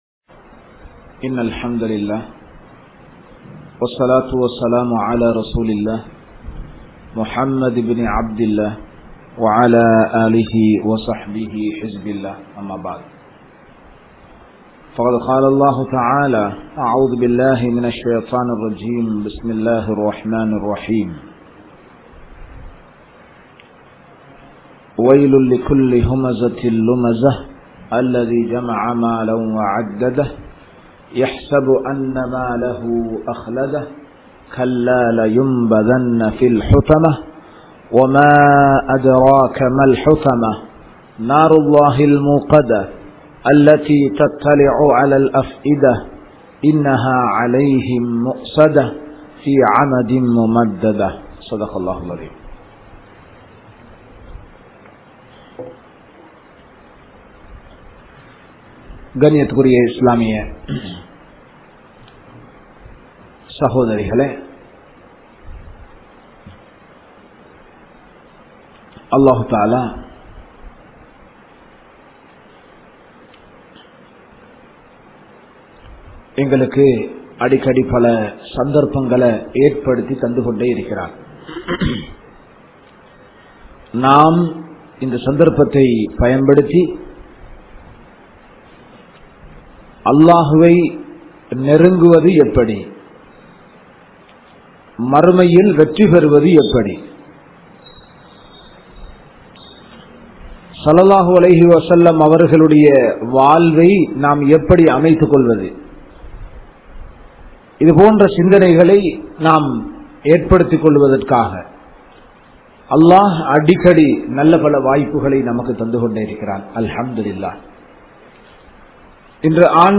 Oru Pen Than Alahai Yaarukku Kaatta Mudium?(ஒரு பெண் தன் அழகை யாருக்கு காட்ட முடியும்?) | Audio Bayans | All Ceylon Muslim Youth Community | Addalaichenai